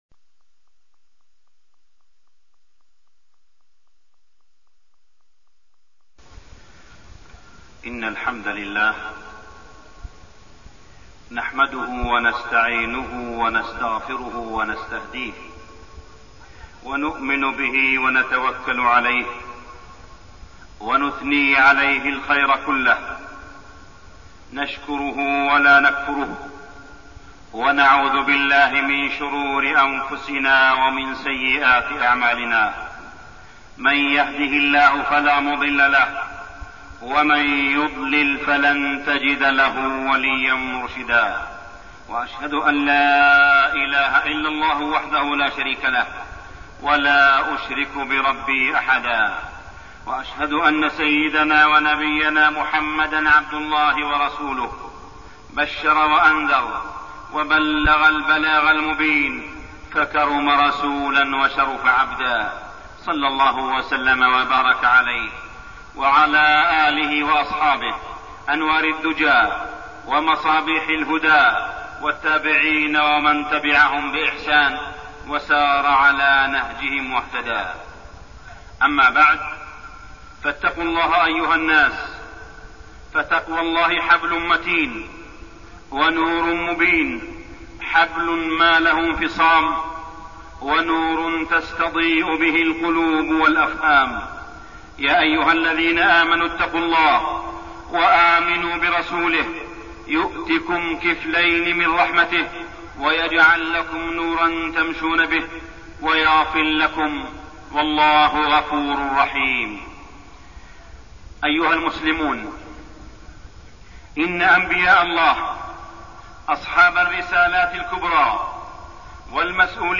تاريخ النشر ٢٦ شوال ١٤١٦ هـ المكان: المسجد الحرام الشيخ: معالي الشيخ أ.د. صالح بن عبدالله بن حميد معالي الشيخ أ.د. صالح بن عبدالله بن حميد سيرة أم المؤمنين خديجة رضي الله عنها